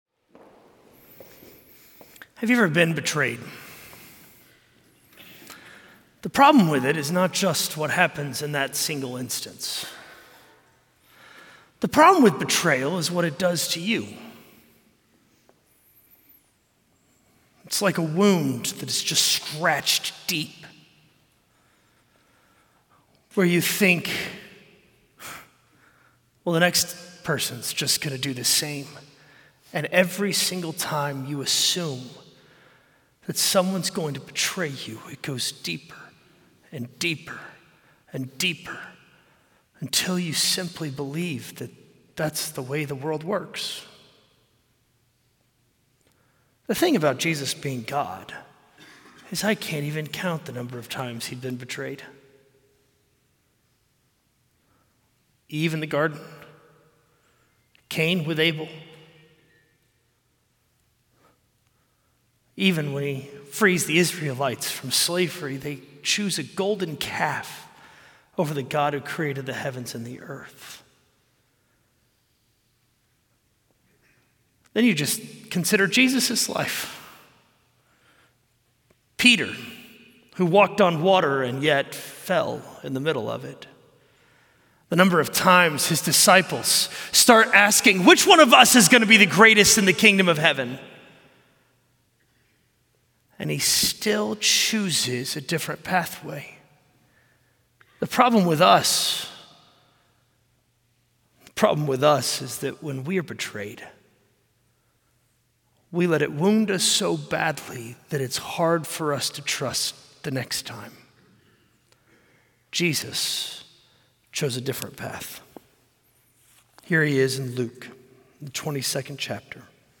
A message from the series "Easter 2025."